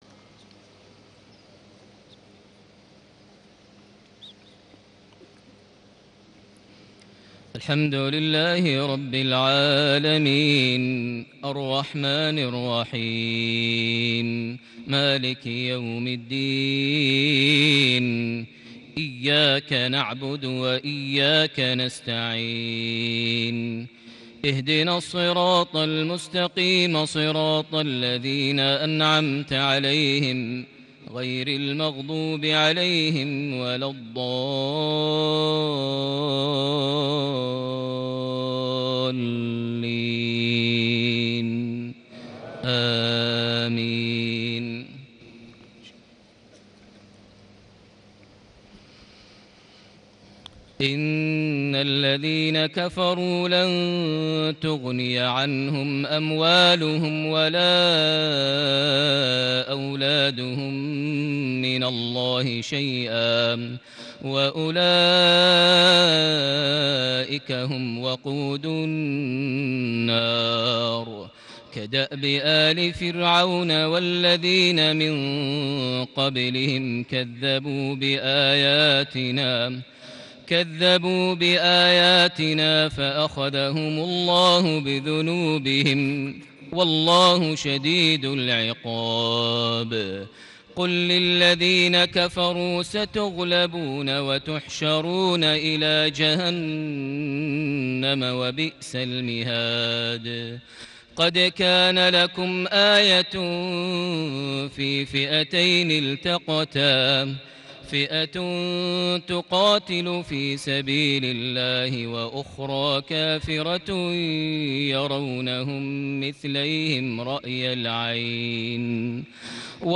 صلاة المغرب ٢٦ ربيع الأول ١٤٣٨هـ سورة آل عمران ١٠-١٧ > 1438 هـ > الفروض - تلاوات ماهر المعيقلي